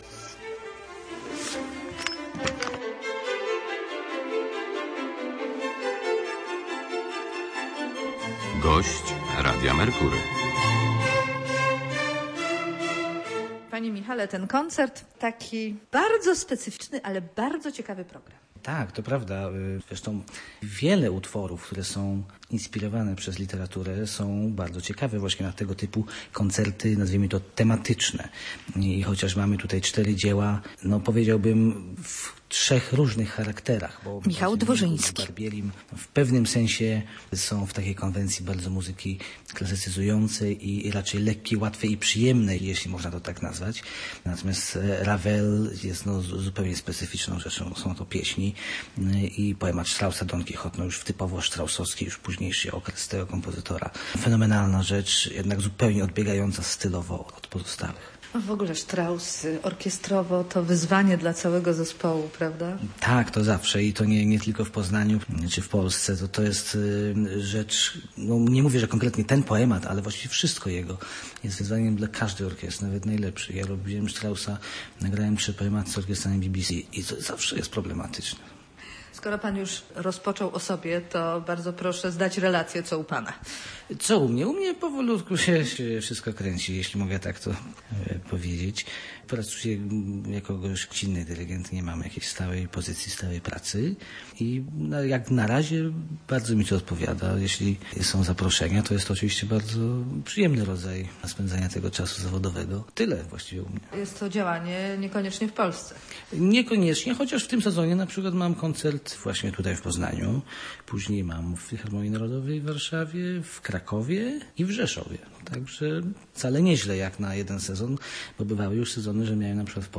rozmawia z dyrygentem